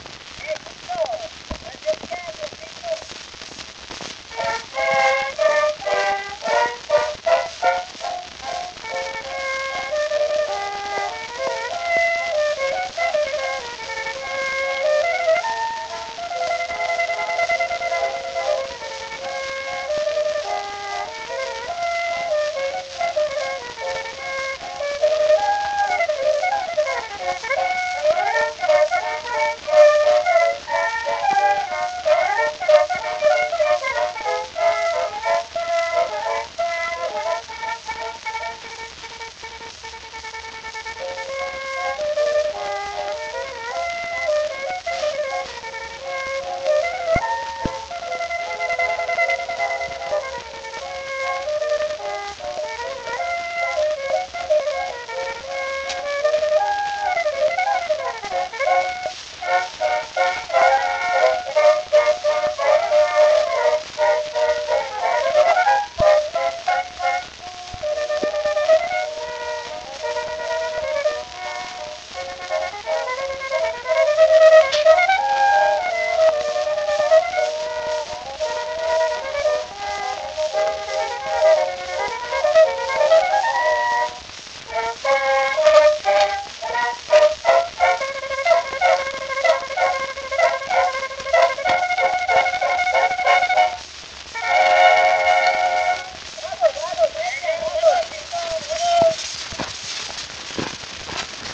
Sie spielten bei der Aufnahme typischerweise in gleichmäßig hoher Lautstärke.
Unbekannte Bläsergruppe: Gouttes d’or.